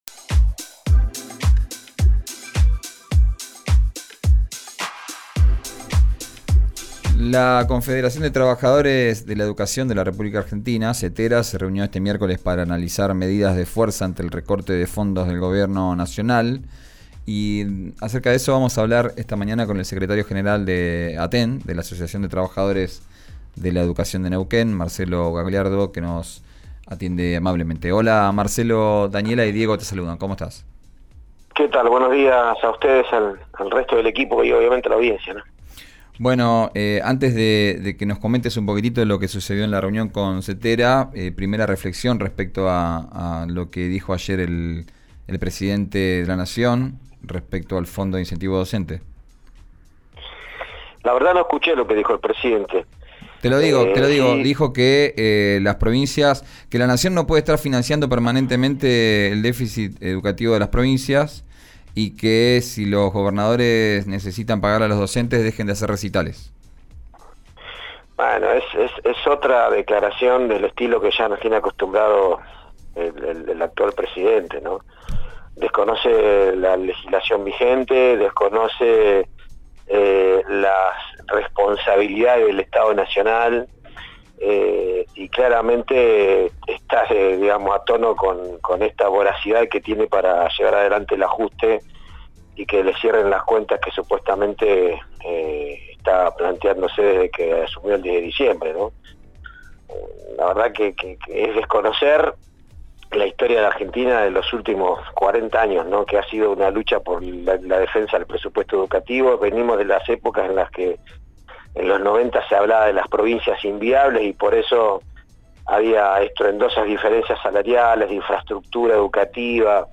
En diálogo con RÍO NEGRO RADIO, también cuestionó la tardanza en la convocatoria por parte del gobierno de Rolando Figueroa.